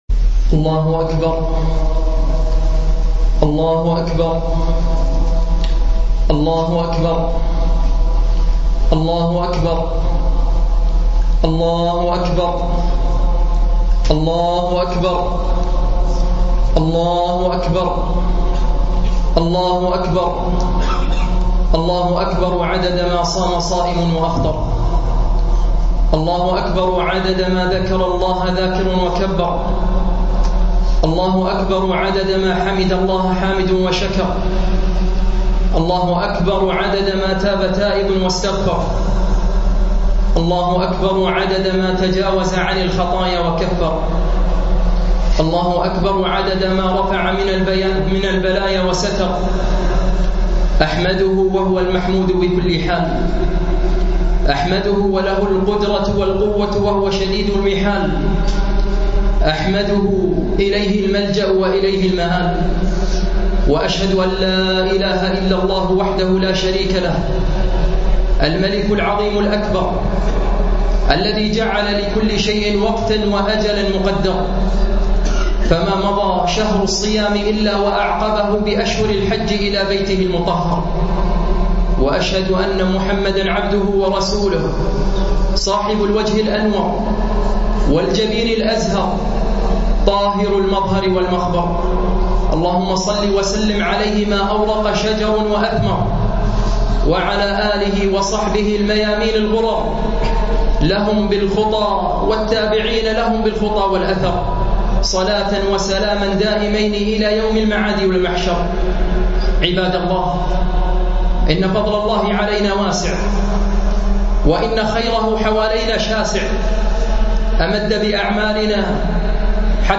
خطبة عيد الفطر 1433